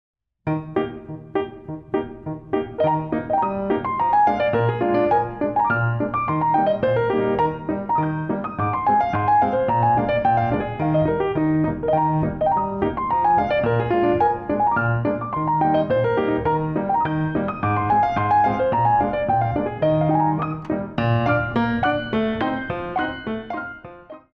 Ballet class music for very young children
FREE AND IMAGINATIVE DANCES